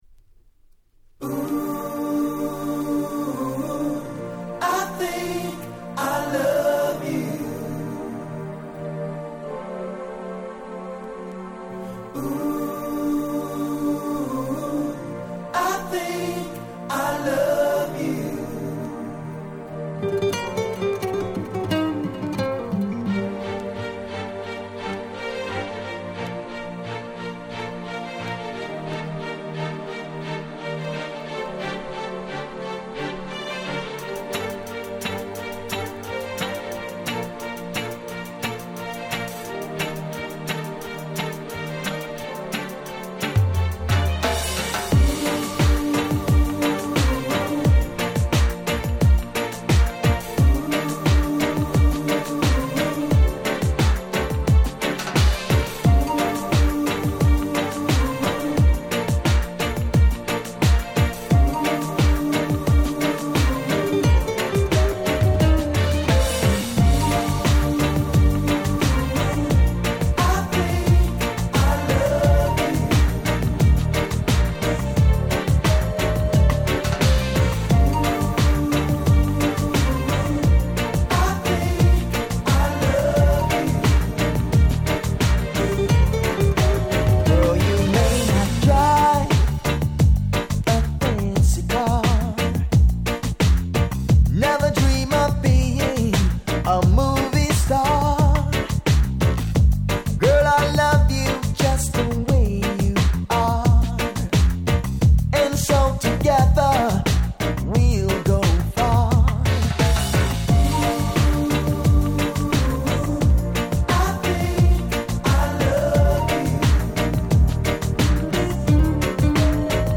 96' Nice Pop Reggae !!
上記の2曲程のはっちゃけ感は無い物の、聴いているだけで何だか楽しい気分になれる夏にぴったりの1曲です！